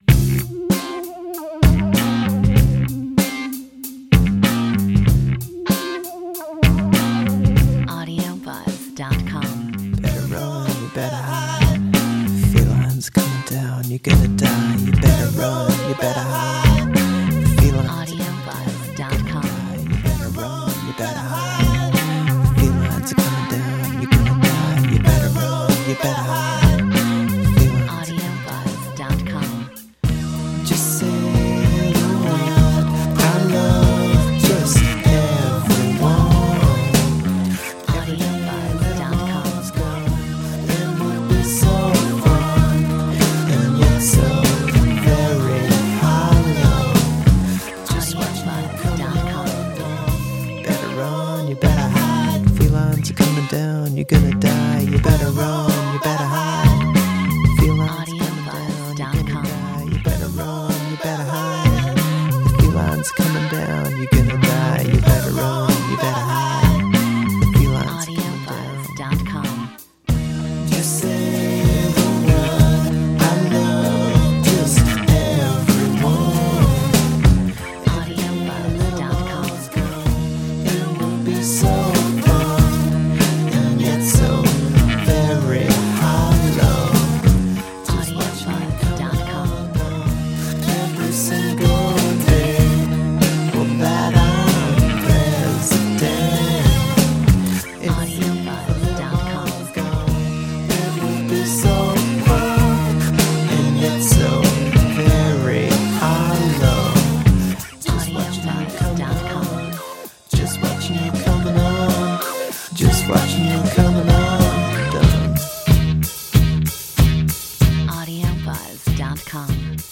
Metronome 96